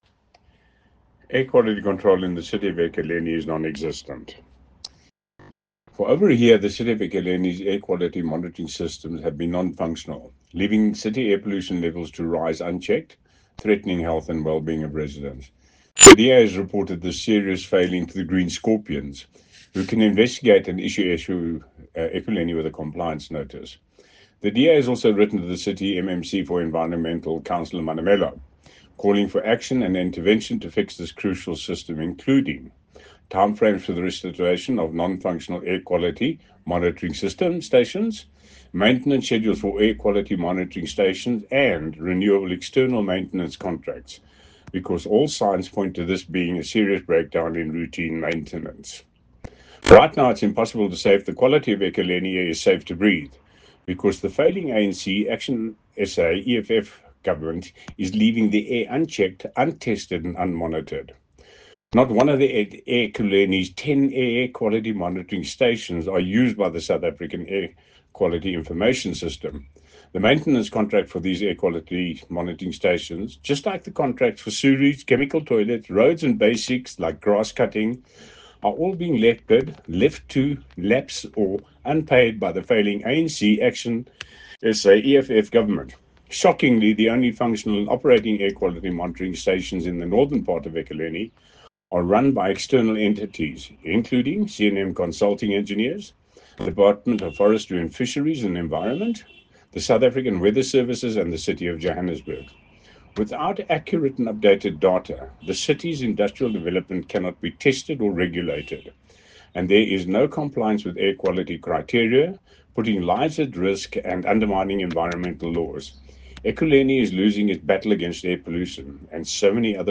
Note to Editors: Please find an English soundbite by Cllr Derek Thomson